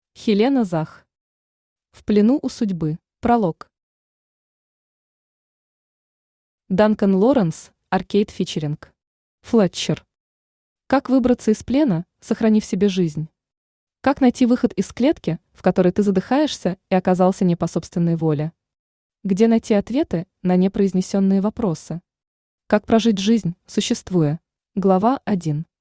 Читает: Авточтец ЛитРес
Аудиокнига «В плену у судьбы».